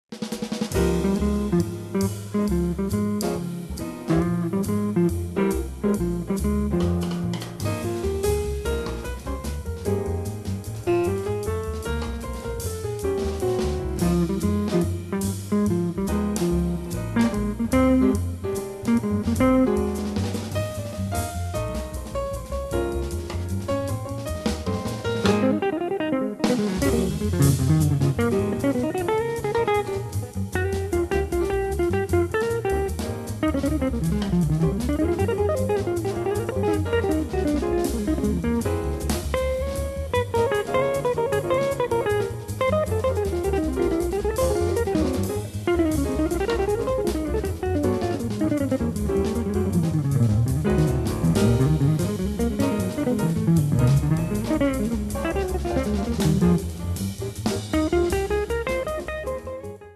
chitarra
pianoforte
contrabbasso
batteria